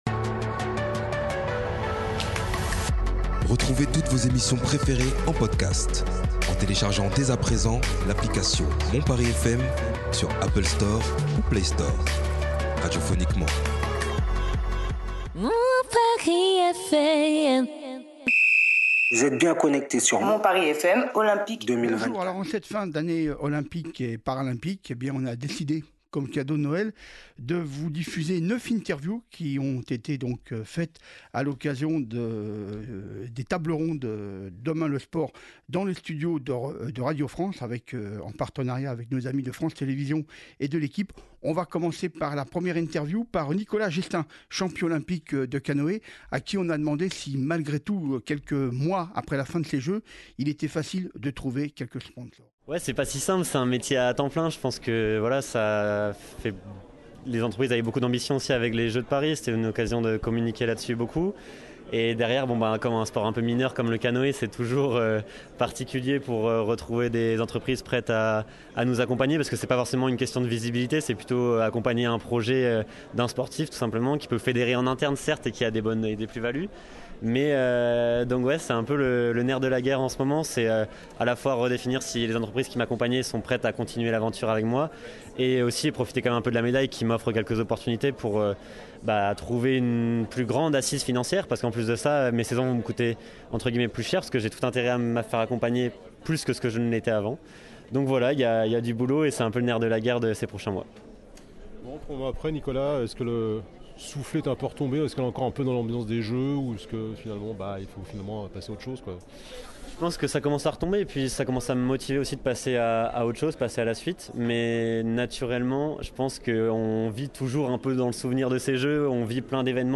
Table Ronde : Demain le Sport !
À l’initiative de Radio France en partenariat avec l’Equipe et France TV, a eu lieu Jeudi 28 Novembre , la troisième édition du Festival *Demain le Sport* sur le thème ( Les Jeux en Héritage ).
Écoutons, au micro de Mon Paris FM, les témoignages des différentes personnalités du monde sportif présentes à cette occasion .